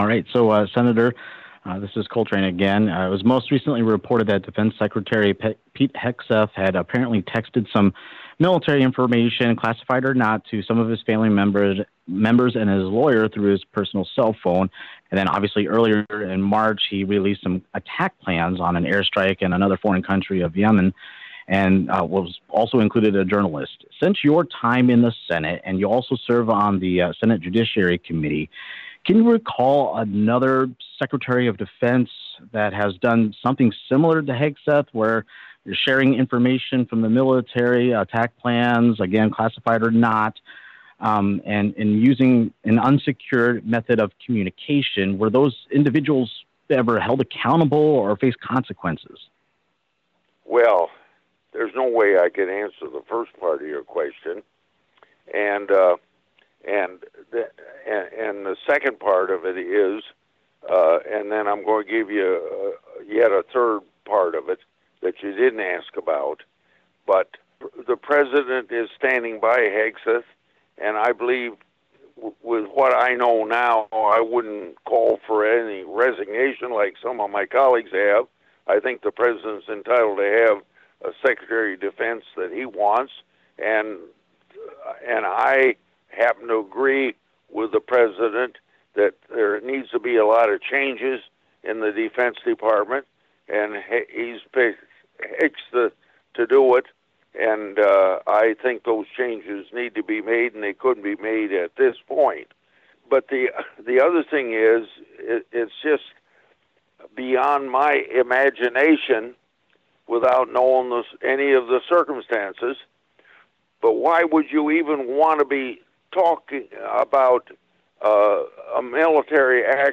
This exchange was part of the latest “Capitol Hill Report.”